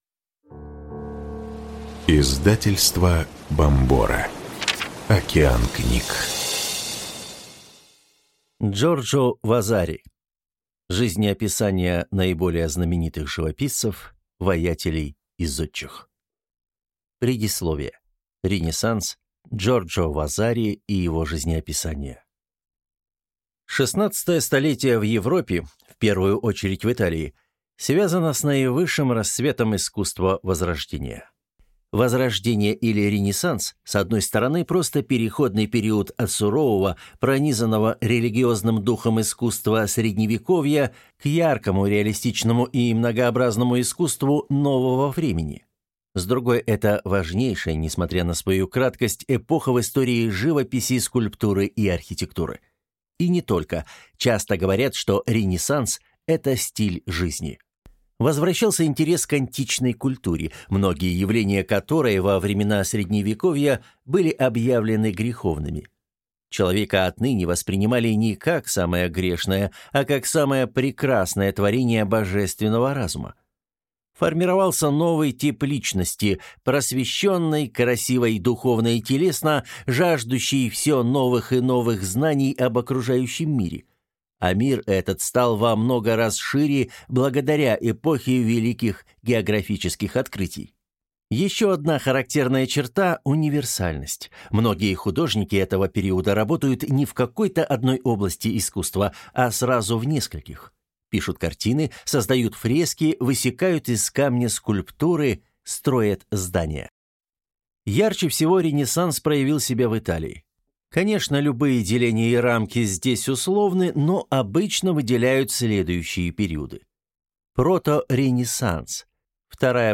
Аудиокнига Жизнеописания наиболее знаменитых живописцев, ваятелей и зодчих | Библиотека аудиокниг